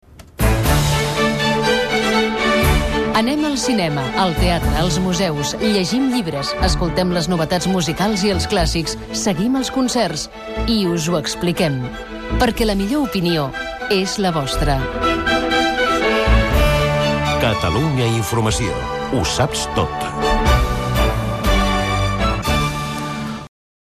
Promoció de l'emissora Gènere radiofònic Publicitat